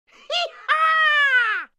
high-score.mp3